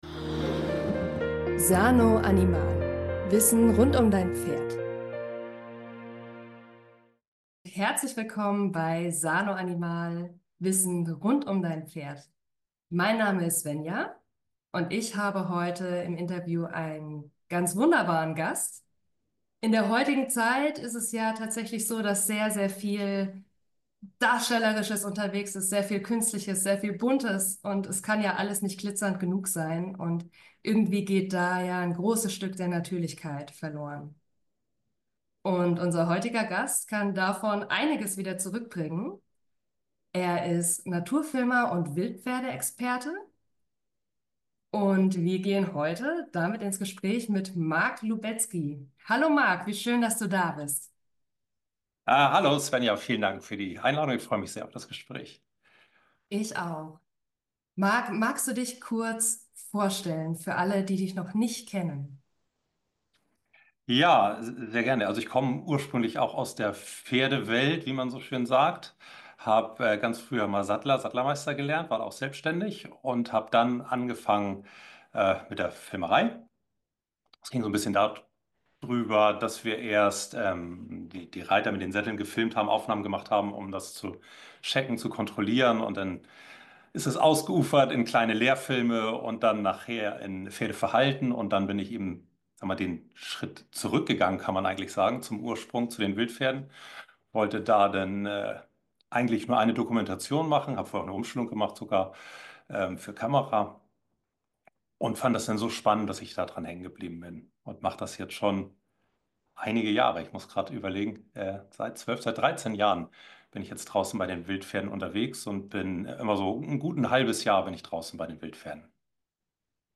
Im Podcast-Interview